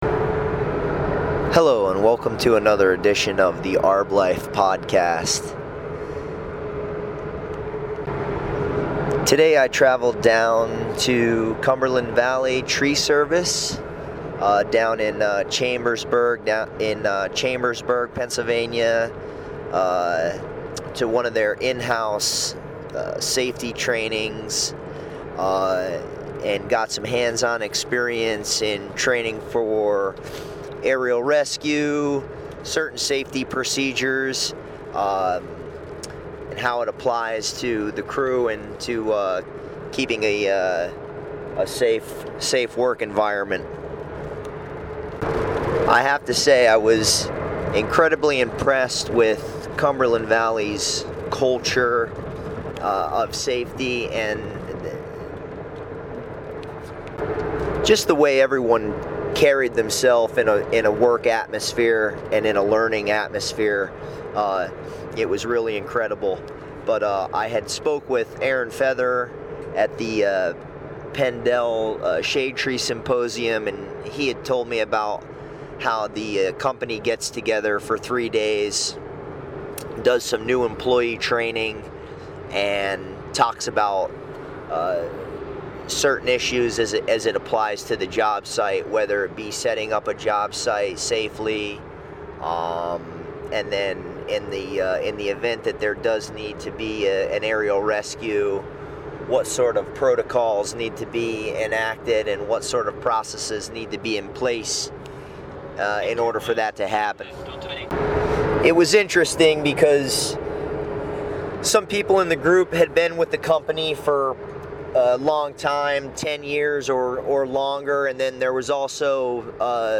CumberlandValleyTrainingDay.mp3